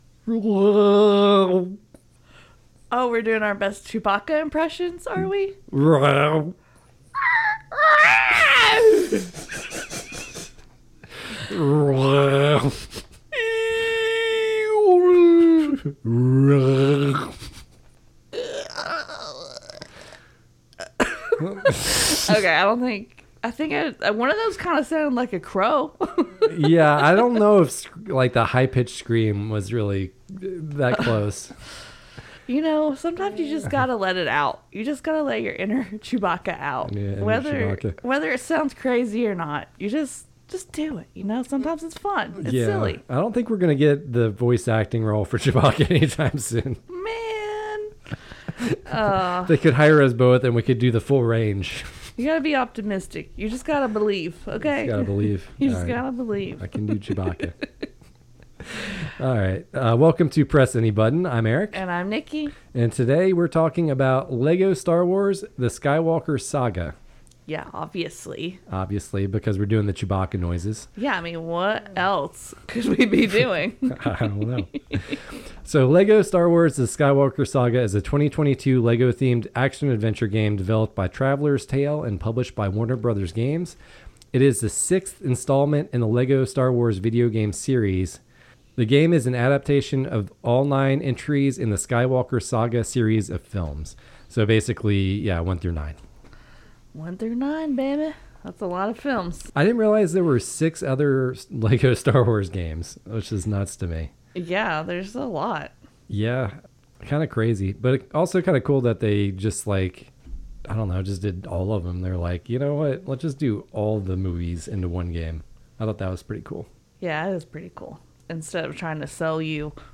do their best (or worst) Chewbacca impressions as they discuss the dark story of Lego Star Wars: The Skywalker Saga. Lego Star Wars: The Skywalker Saga is a 2022 Lego-themed action-adventure game developed by Traveller’s Tales and published by Warner Bros. Games.